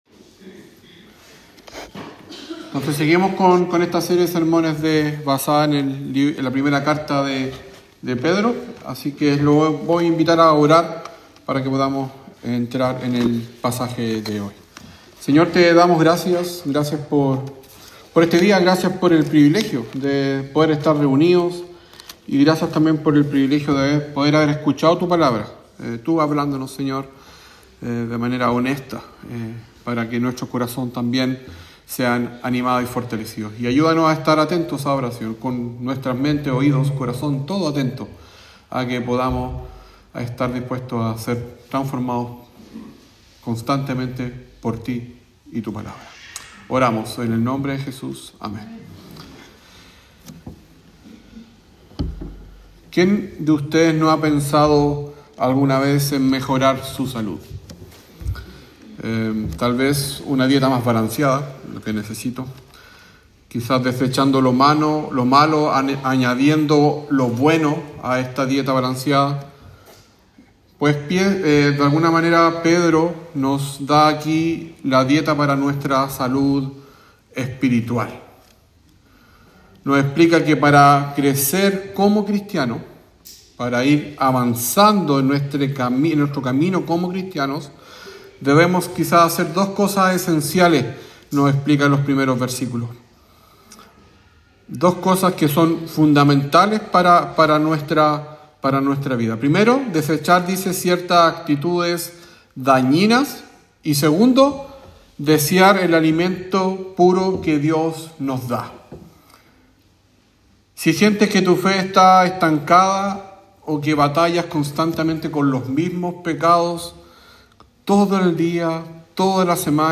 Website de la Iglesia St. James de Punta Arenas Chile
Sermón sobre 1 Pedro 2